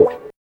95 GTR 3  -L.wav